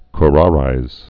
(k-rärīz, ky-)